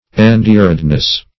Endearedness \En*dear"ed*ness\, n.